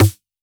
RDM_Copicat_MT40-Snr03.wav